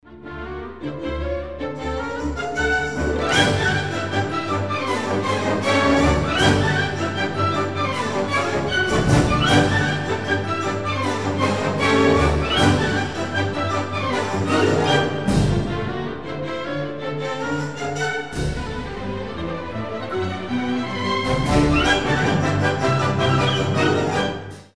из фильмов
бодрые
озорные
оркестр
рождественские